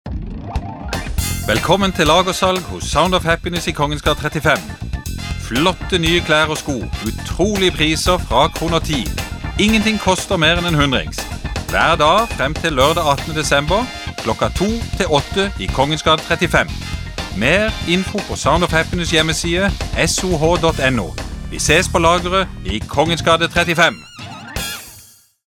Reklame - SOH Lagersalg.mp3